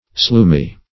sloomy - definition of sloomy - synonyms, pronunciation, spelling from Free Dictionary Search Result for " sloomy" : The Collaborative International Dictionary of English v.0.48: Sloomy \Sloom"y\, a. Sluggish; slow.